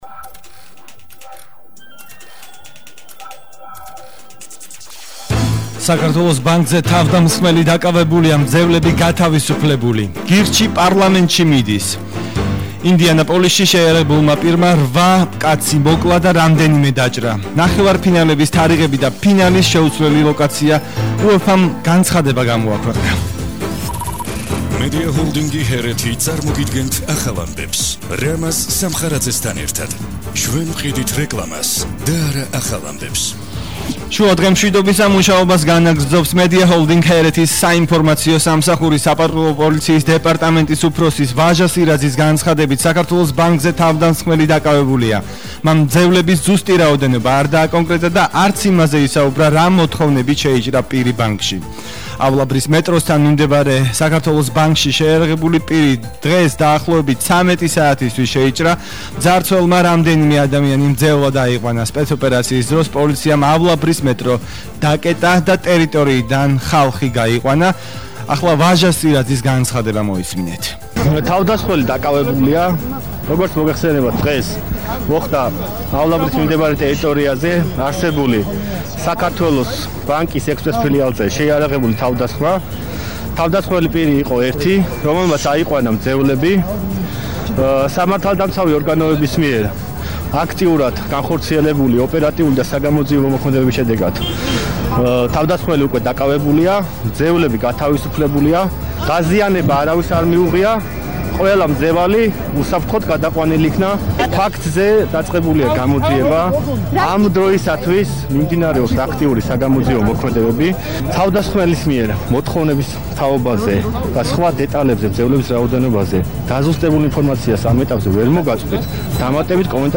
ახალი ამბები 15:00 საათზე –16/04/21